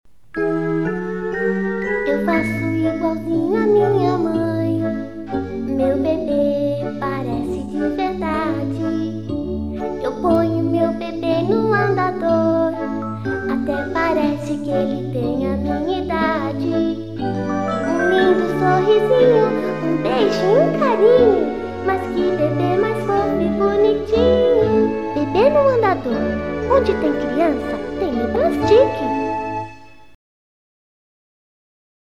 Commercieel, Natuurlijk, Vertrouwd, Zacht, Zakelijk